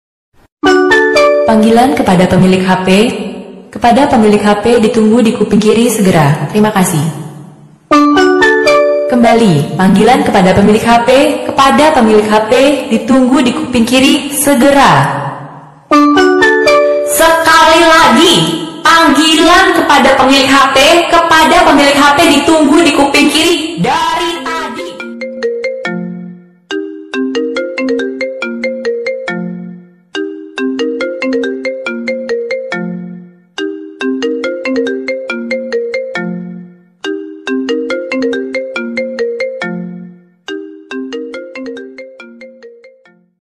Nada dering Panggilan kepada pemilik hp Kiw kiw Nada dering lucu Pakai ada WA
Kategori: Nada dering